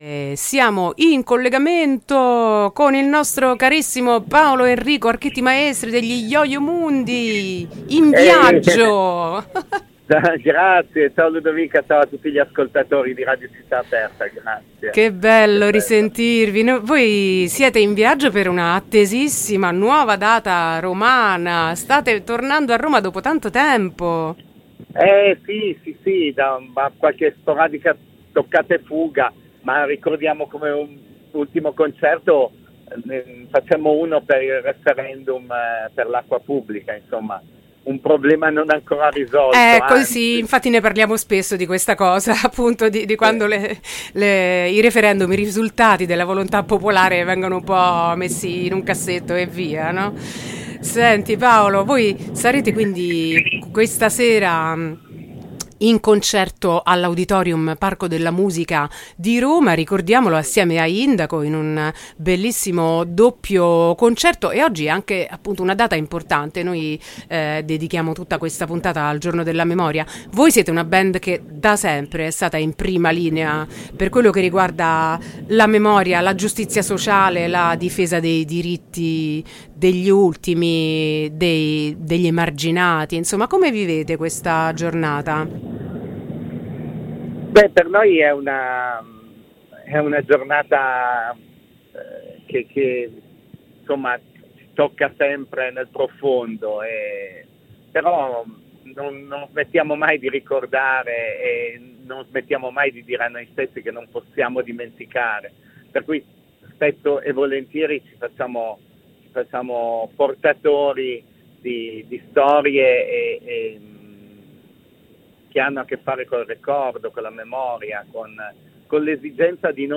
intervista-yoyomundi-27-1-23.mp3